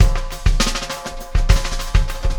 Extra Terrestrial Beat 11.wav